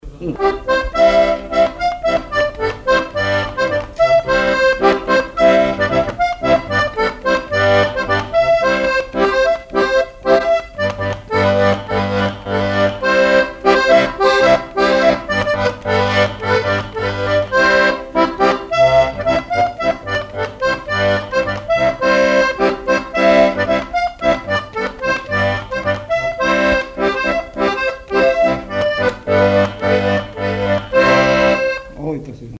Chants brefs - A danser
Chansons et répertoire du musicien sur accordéon chromatique
Pièce musicale inédite